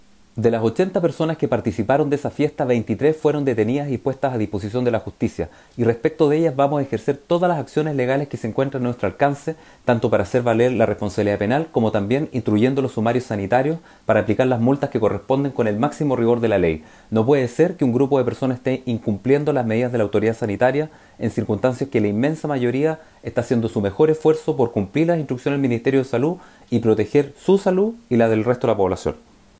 Desde el Gobierno Regional, el Intendente de Atacama se refirió a esta este evento ilegal, comentando que se ejecutarán las acciones legales que correspondan en este caso: